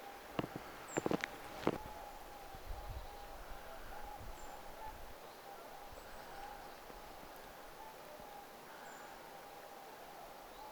tietääkseni ti-puukiipijälintu
tietaakseni_ti-puukiipija.mp3